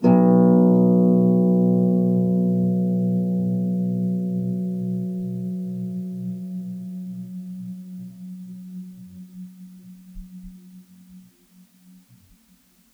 KSHarp_B1_mf.wav